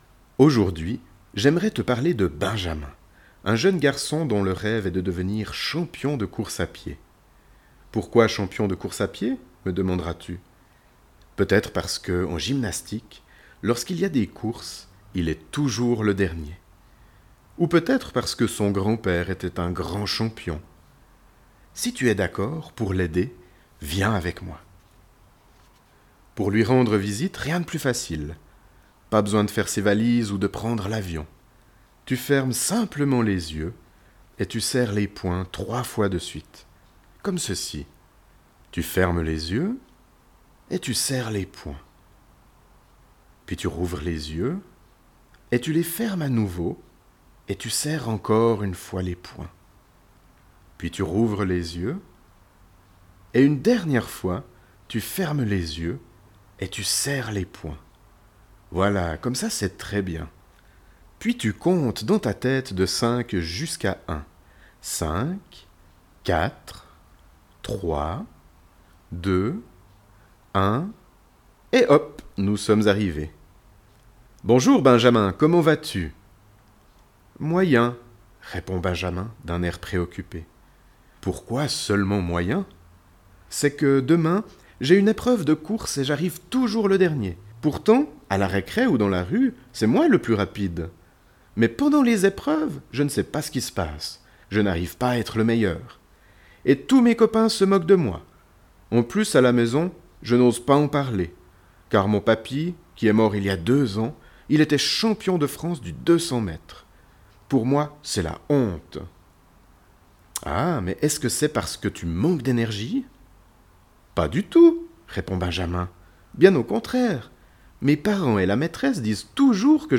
Ces audios sont des contes métaphoriques à destination des enfants.